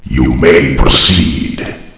flak_m/sounds/announcer/int/proceed.ogg at 098bc1613e970468fc792e3520a46848f7adde96